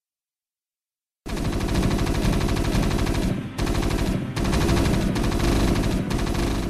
Likewise the original marine rifles had a hefty sound instead of the later light pattering.
Marine Rifle